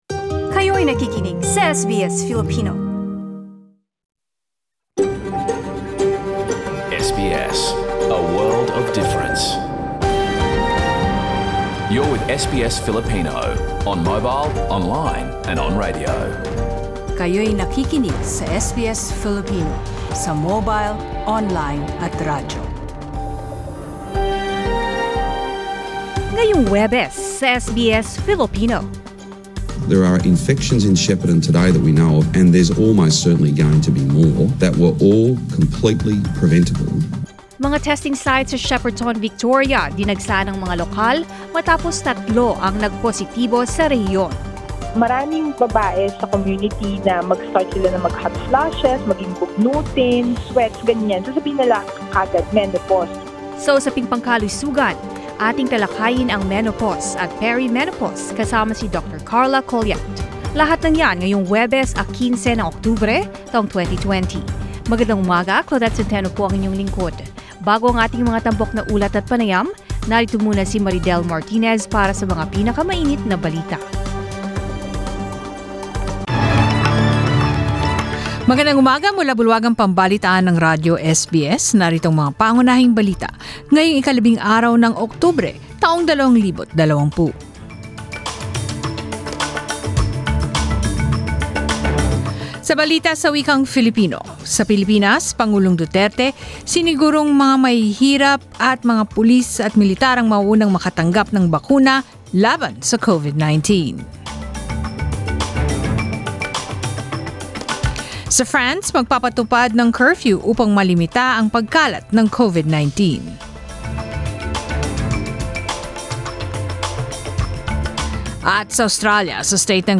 Balita ngayon ika 15 ng Oktubre